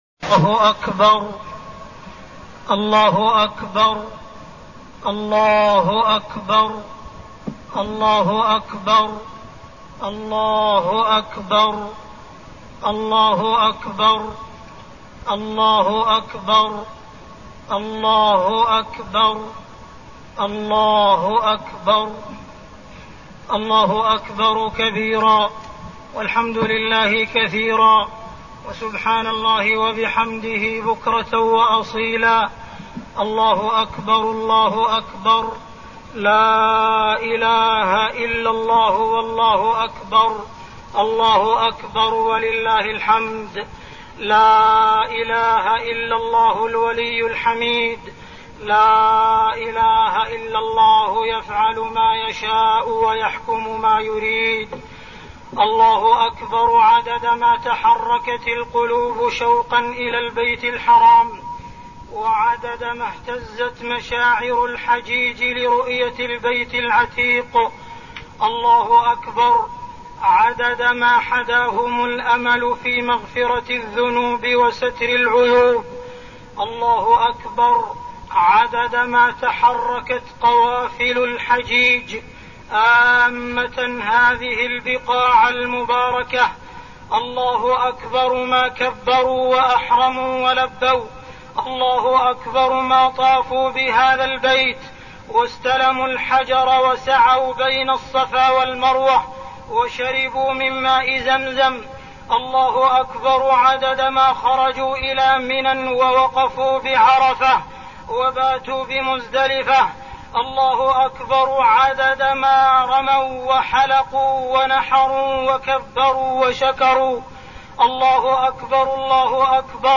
خطبة عيد الإضحى-البوسنة والهرسك
تاريخ النشر ١٠ ذو الحجة ١٤١٢ هـ المكان: المسجد الحرام الشيخ: معالي الشيخ أ.د. عبدالرحمن بن عبدالعزيز السديس معالي الشيخ أ.د. عبدالرحمن بن عبدالعزيز السديس خطبة عيد الإضحى-البوسنة والهرسك